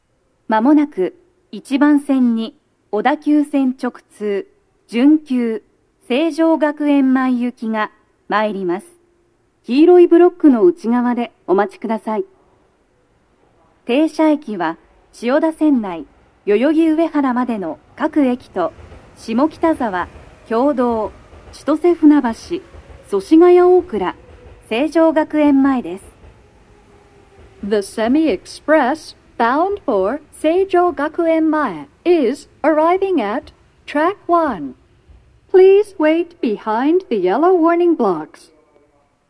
鳴動中に入線してくる場合もあります。
女声
接近放送3